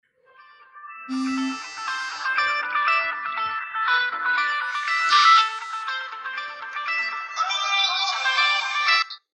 Тон дзвінка + Вібрація